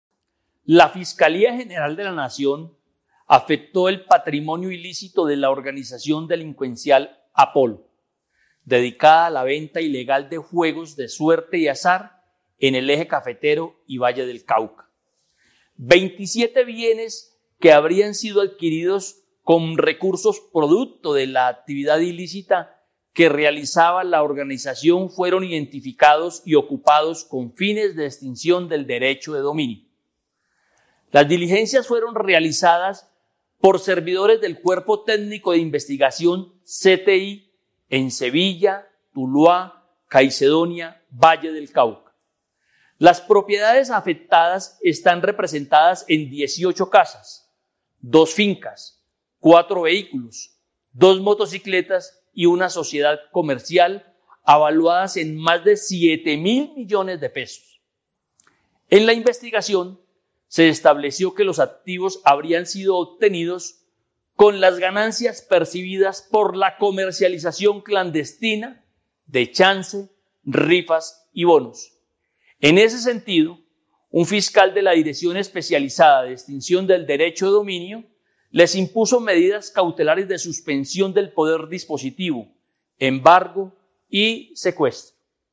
José Iván Caro, director Extinción Dominio, Fiscalía